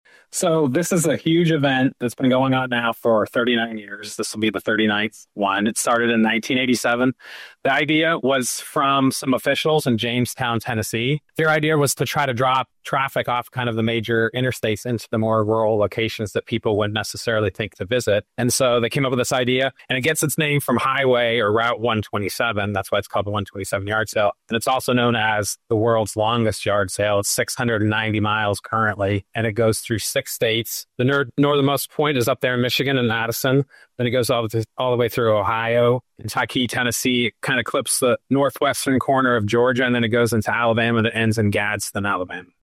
127-interview